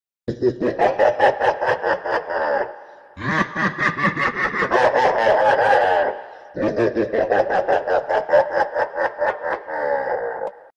Evil Laugh Sound
horror
Evil Laugh